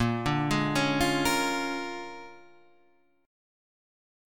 A#7#9 chord {6 5 6 6 6 6} chord